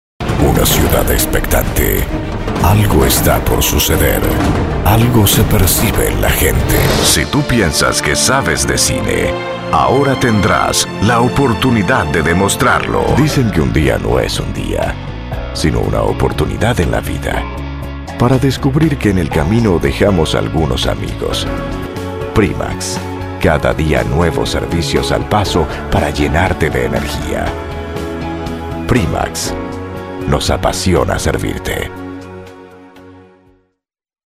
Warm and deep voice for narrations, documentaries, trailers, commercials and promos.
Sprecher für castellanisch, spanisch, portugiesisch und französisch
Sprechprobe: Werbung (Muttersprache):